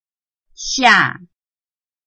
拼音查詢：【四縣腔】xia ~請點選不同聲調拼音聽聽看!(例字漢字部分屬參考性質)